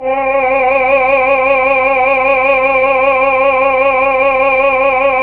VOX_Chb Ml C_4-L.wav